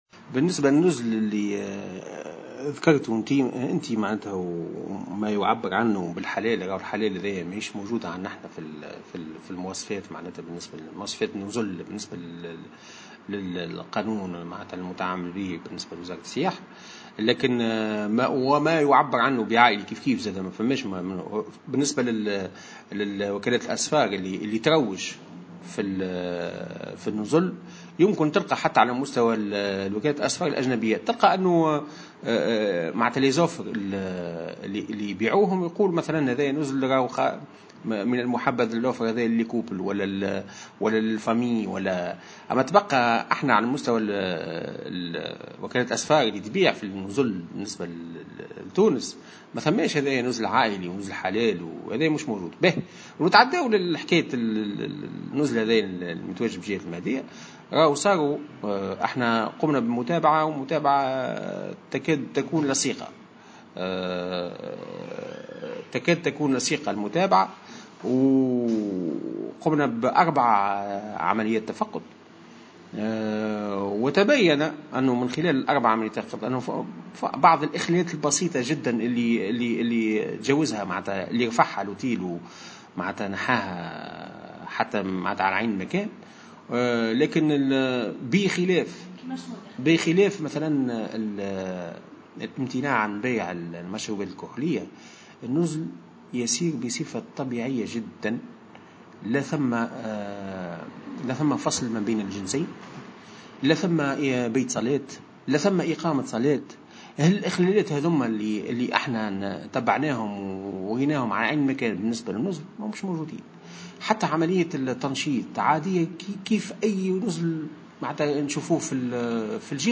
واكد الحلوي في تصريح لمراسل الجوهرة أف أم، اليوم الثلاثاء، إن المندوبية الجهوية تتابع "بصفة تكاد لصيقة" لهذا النزل من خلال القيام بأربع عمليات تفقد تبيّن خلالها وجود "إخلالات بسيطة جدا" تم تداركها، على غرار وضع لافتة في واجهته تتضمن عبارة "نزل عائلي"، مشيرا إلى أن عمل النزل يسير بصفة طبيعية كغيره من النزل.